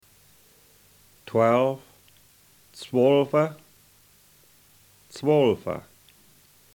Puhoi Egerländer Dialect